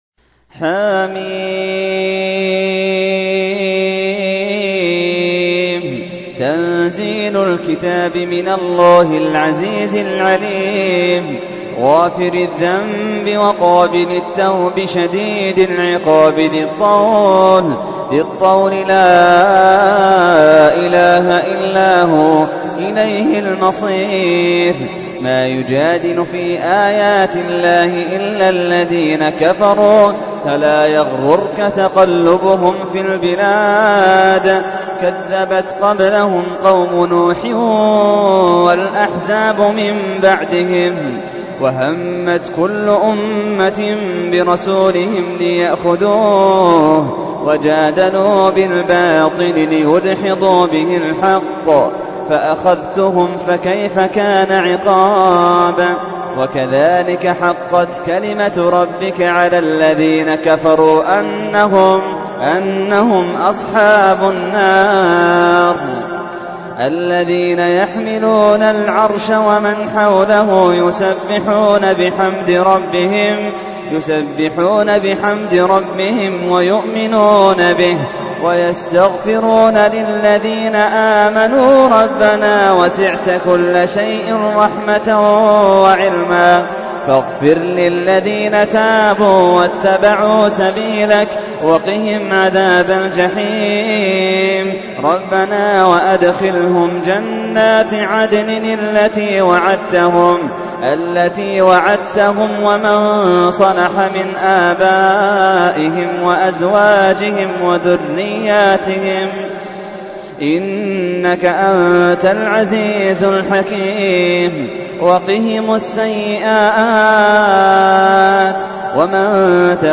تحميل : 40. سورة غافر / القارئ محمد اللحيدان / القرآن الكريم / موقع يا حسين